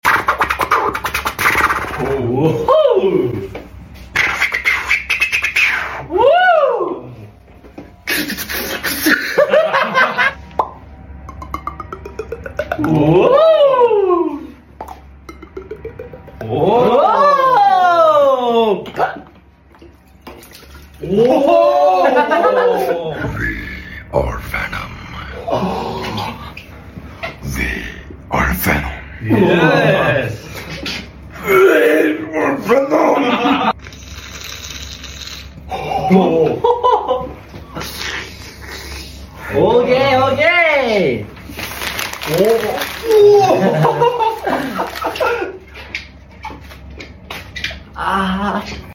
Beat box Battle!!